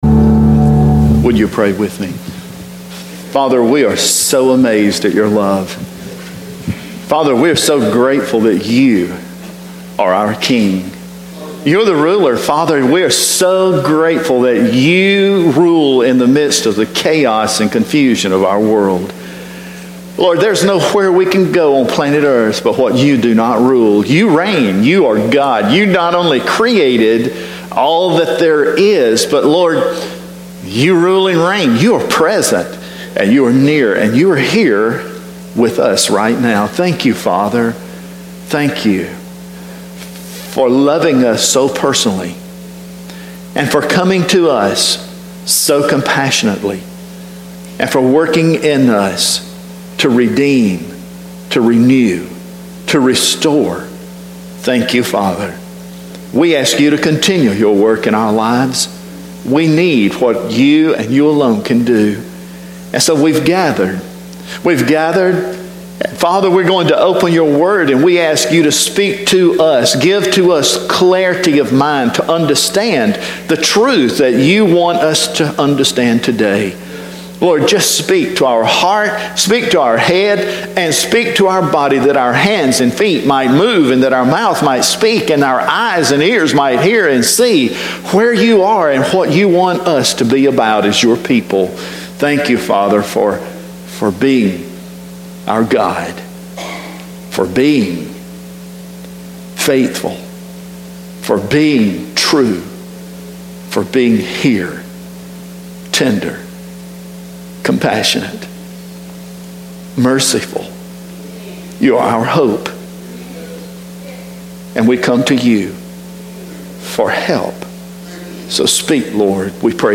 Aug 27-sermon....mp3